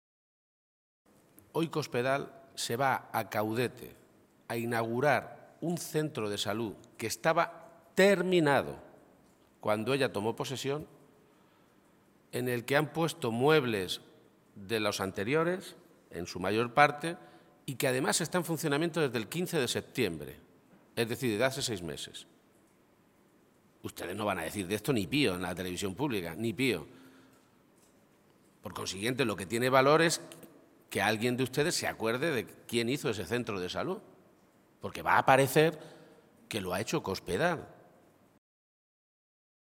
García-Page se pronunciaba de esta manera esta mañana, en Toledo, en una comparecencia ante los medios de comunicación que ha tenido un gran contenido relacionado con asuntos sanitarios.
Cortes de audio de la rueda de prensa